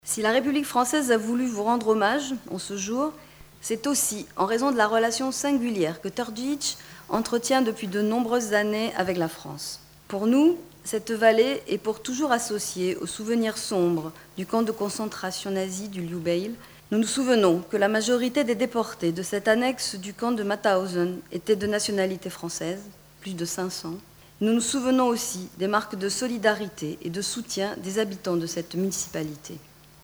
76743_izjavafrancoskeveleposlanicemarionparadas.mp3